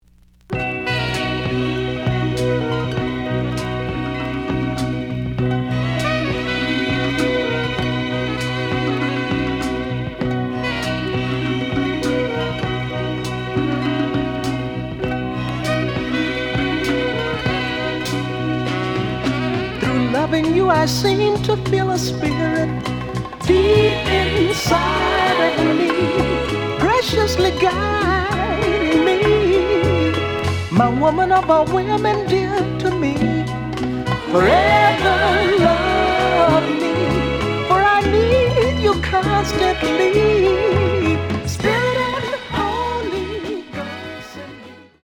The audio sample is recorded from the actual item.
●Genre: Funk, 70's Funk
Some noise on beginnig of A side, but almost good.